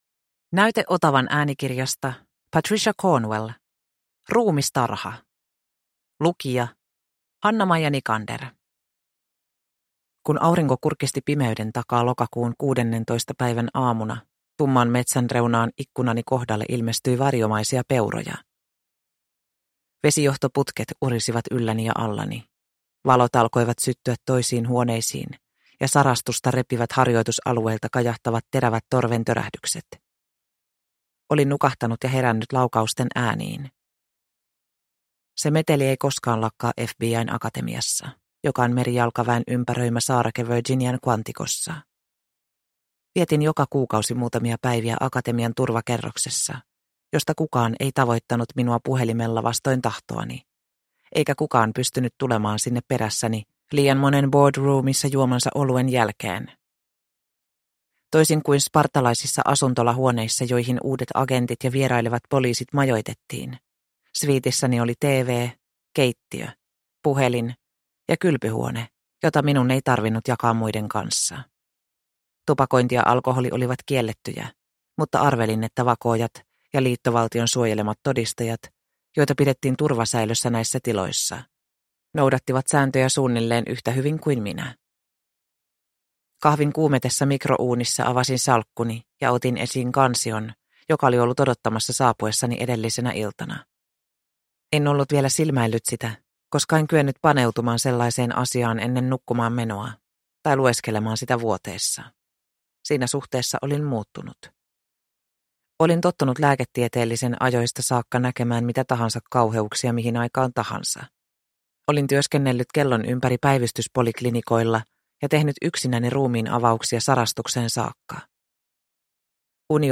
Ruumistarha – Ljudbok – Laddas ner